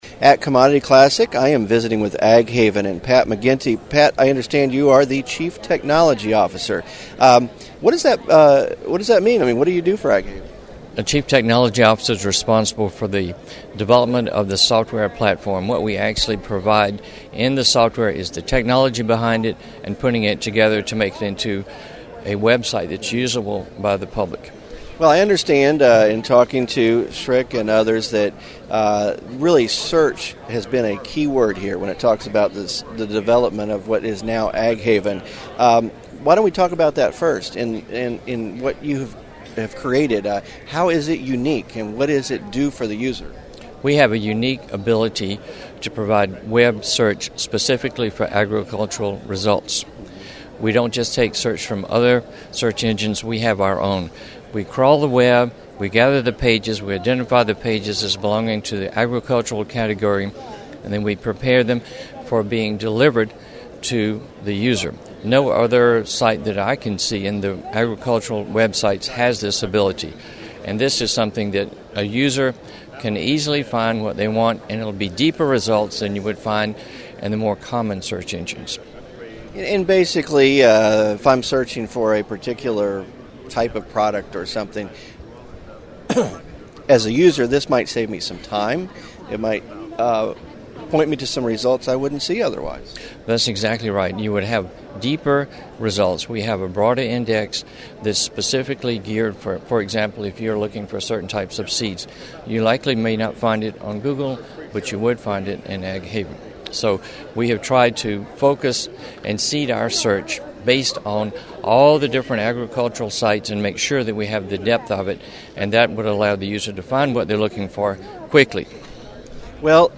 Interview
AgWired coverage of the 2011 Commodity Classic